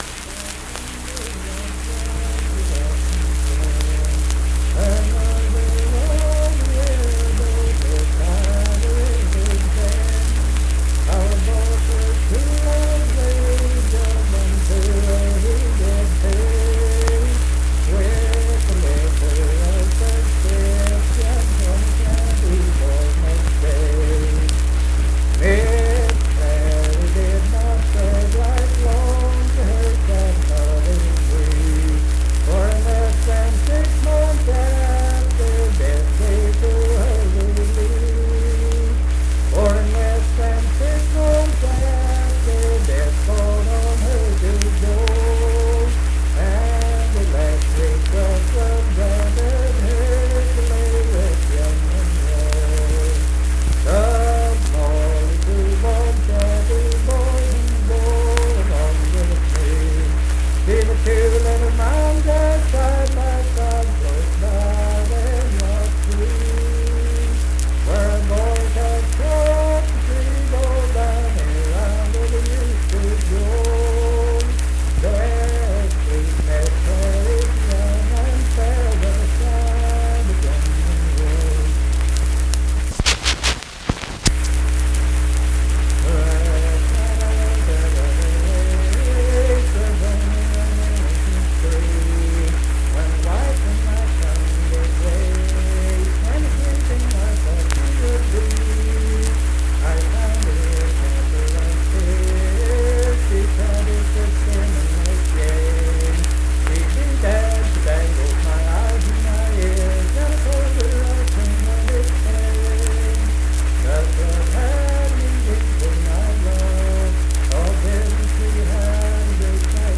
Unaccompanied vocal music
Verse-refrain 5(4)&R(4). Performed in Kanawha Head, Upshur County, WV.
Voice (sung)